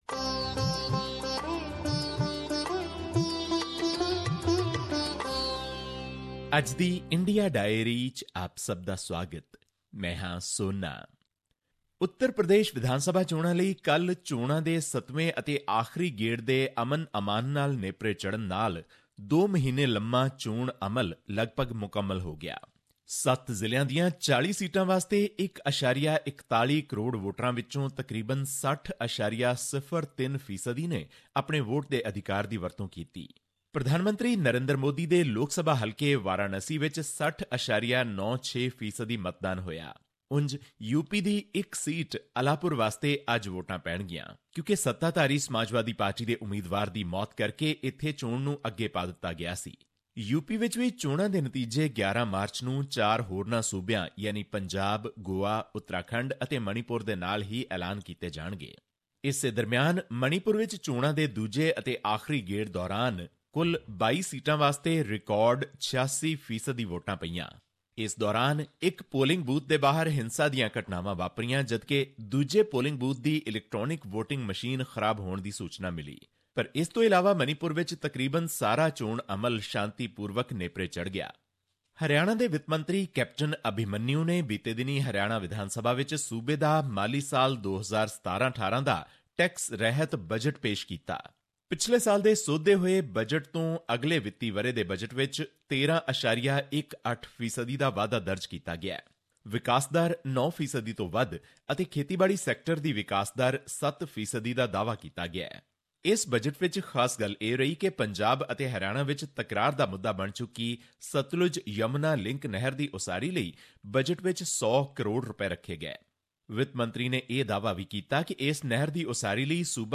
His report was presented on SBS Punjabi program on Thursday, March 09, 2017, which touched upon issues of Punjabi and national significance in India. Here's the podcast in case you missed hearing it on the radio.